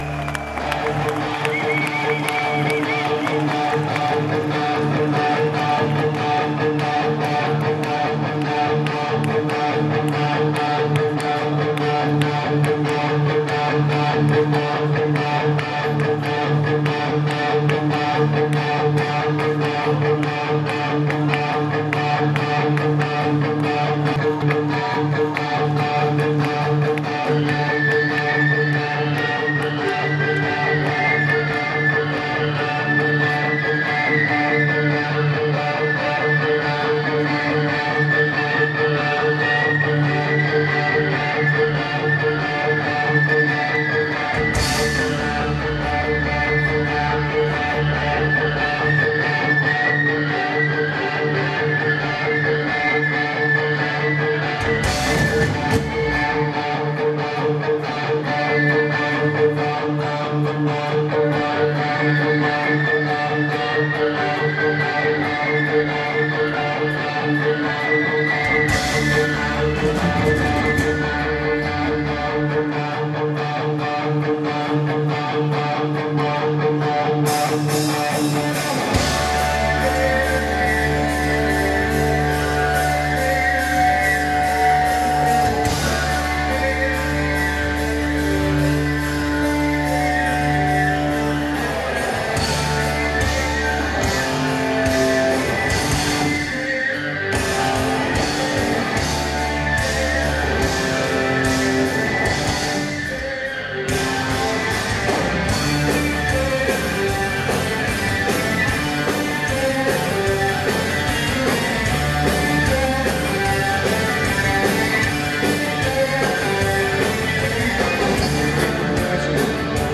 Amsterdam 26.09.2005